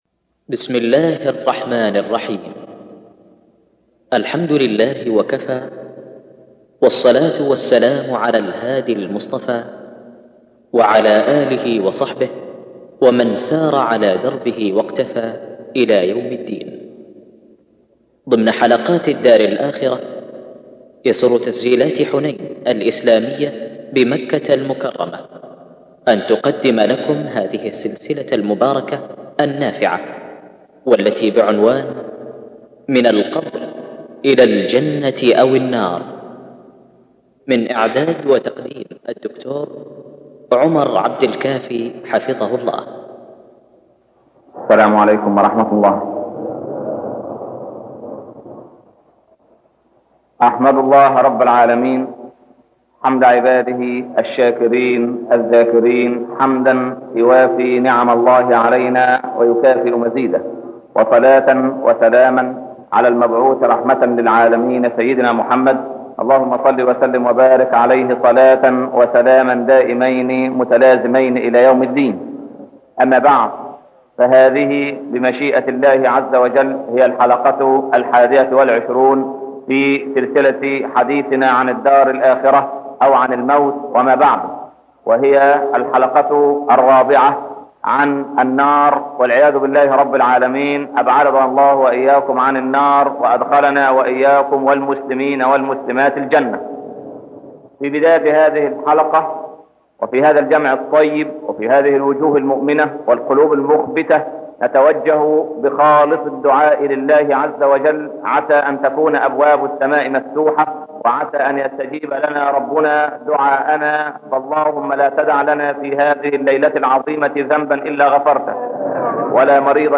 الدرس العشرون - الشيخ عمر بن عبدالكافي